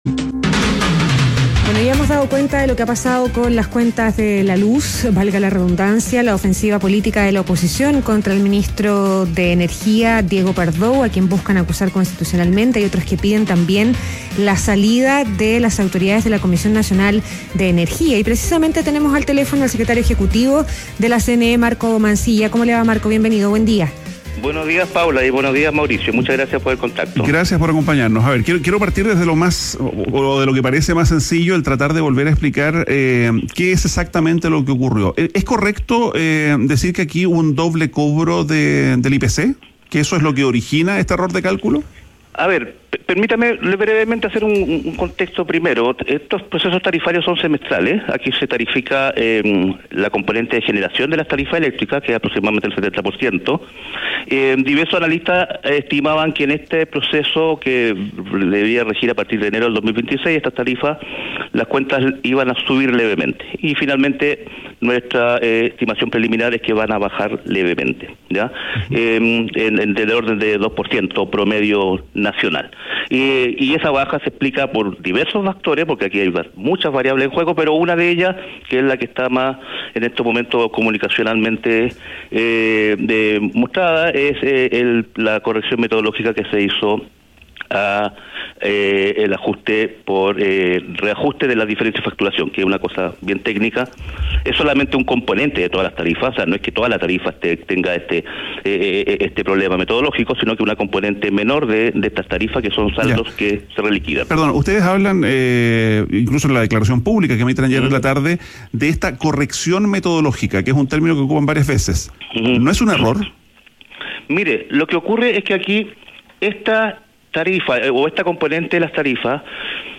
Marco Mancilla, secretario ejecutivo de la CNE conversa con ADN Hoy